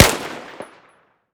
m4a1_3.ogg